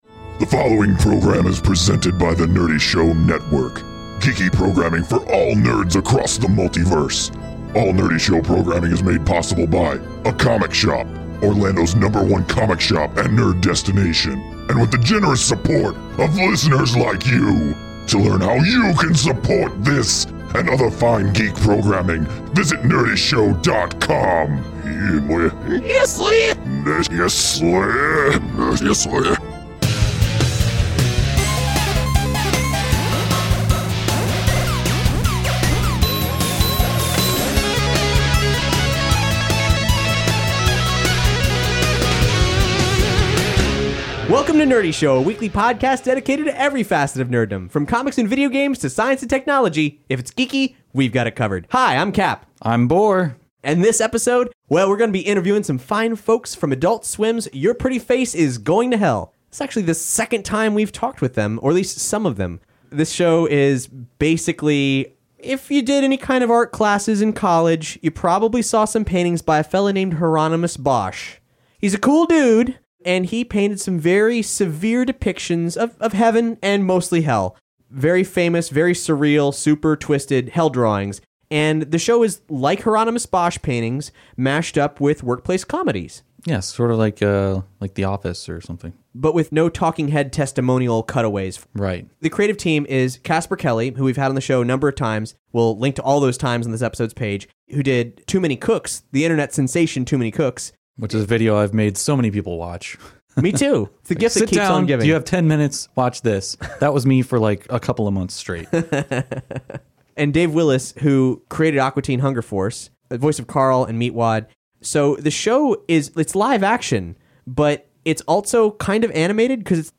Nerdy Show Interviews: The Cast & Creators of Your Pretty Face is Going to Hell